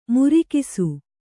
♪ murikisu